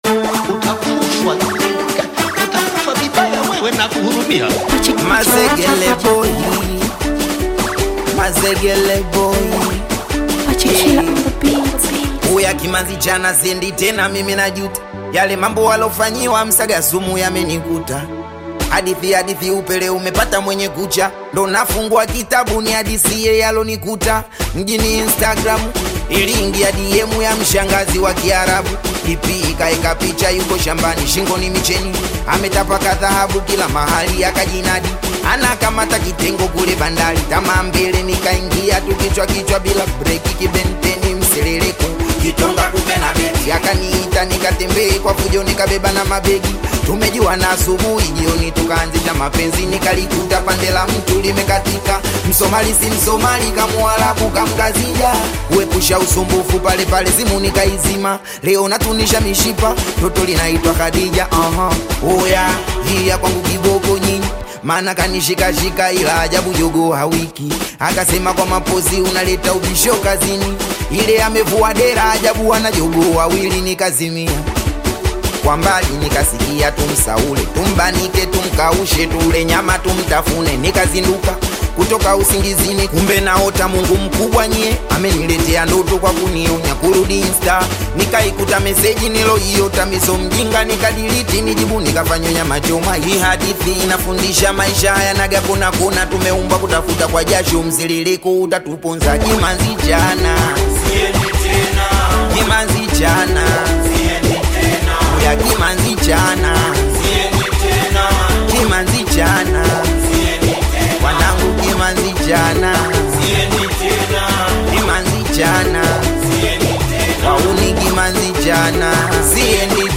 direct lyrics, catchy melodies, and modern production